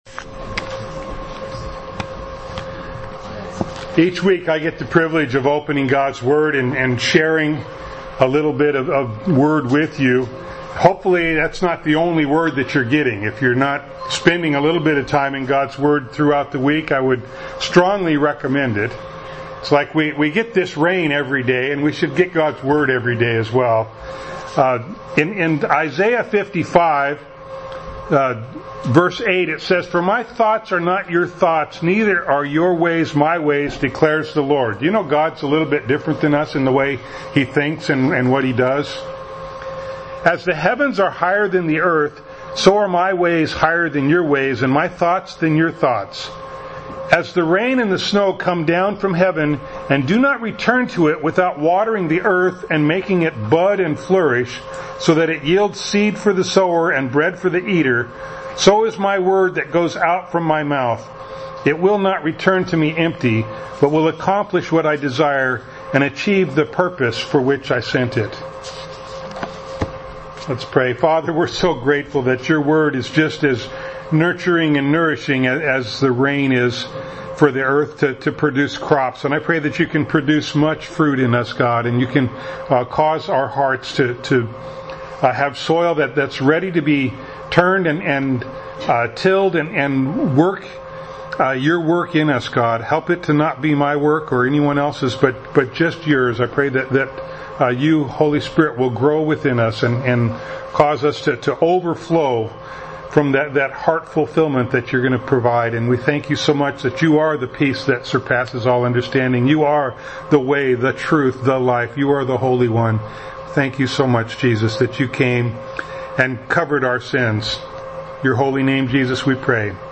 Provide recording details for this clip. Passage: John 1:35-51 Service Type: Sunday Morning